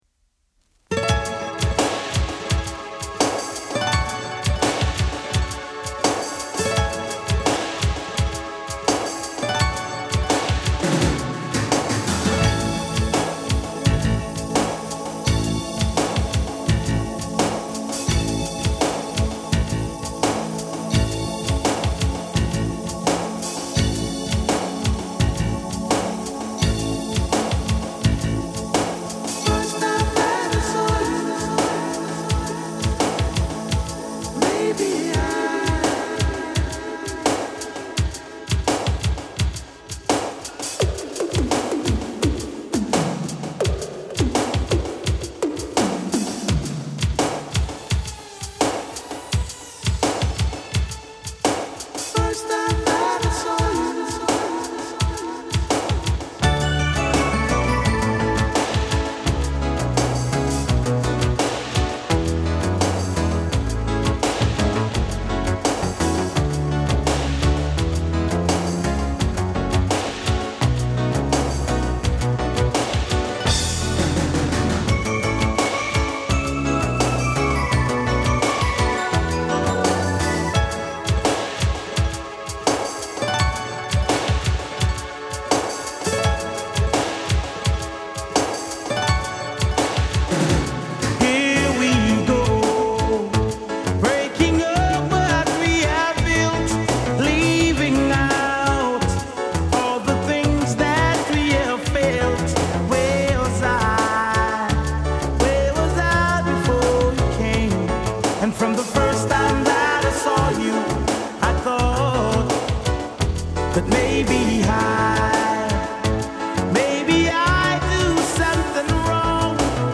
イントロのロング・アレンジが素晴らしいメロウ・ソウル・レゲエのバイブル的決定版！